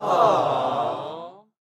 Умиление - Альтернативный вариант